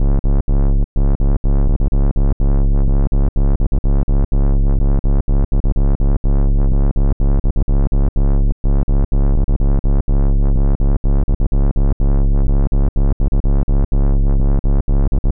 • Tech House Bass Rhythm Punchy - C sharp.wav
Loudest frequency 83 Hz
Tech_House_Bass_Rhythm_15_Punchy_-_C_sharp_6L5.wav